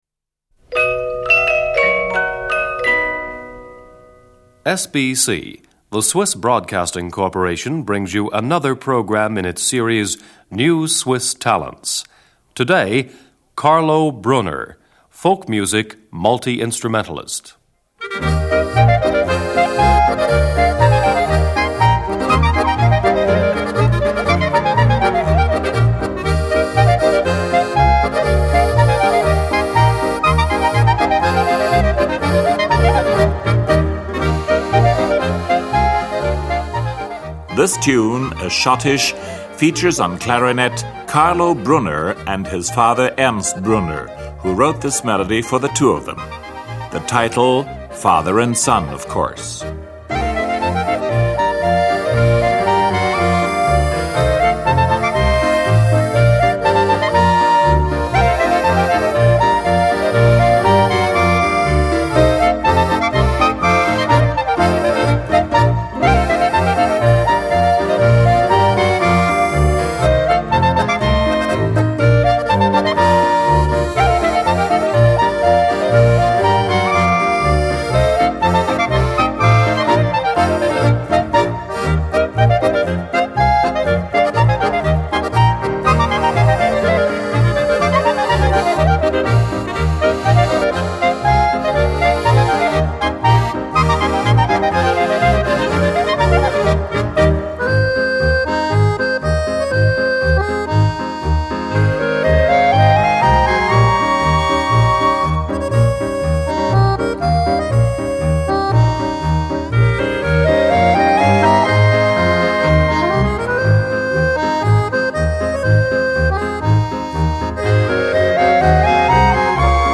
Schottisch
clarinets.
alto sax.
soprano sax.
accordion.
piano.
bass.
Playback recording procedure.
2 alto saxes, 2 tenor saxes, baritone sax, clarinet.
Ländler